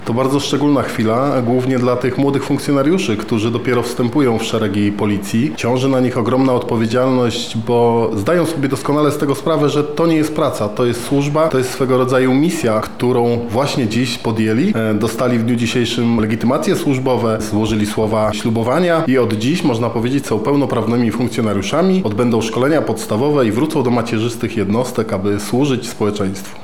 Ceremonia zaprzysiężenia miała miejsce w Komendzie Wojewódzkiej Policji w Lublinie.
Swoimi wrażeniami podzielił się z nami również jeden ze ślubujących: